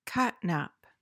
catnap.mp3